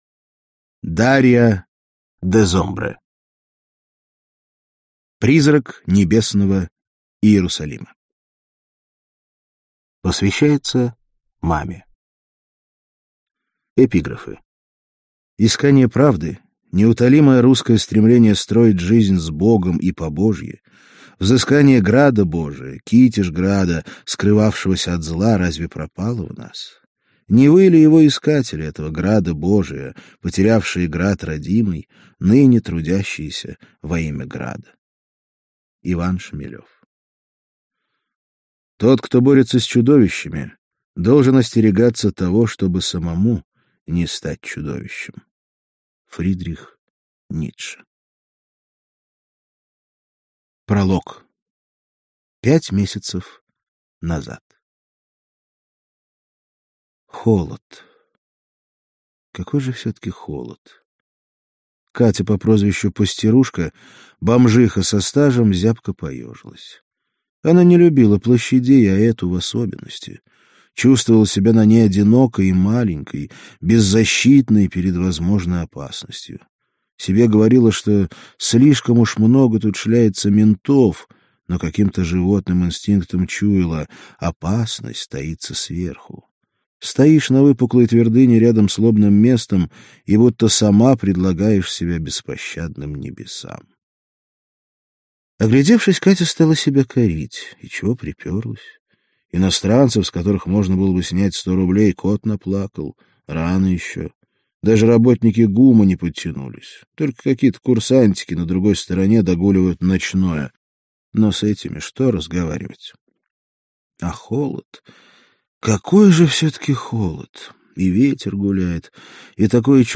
Аудиокнига Призрак Небесного Иерусалима - купить, скачать и слушать онлайн | КнигоПоиск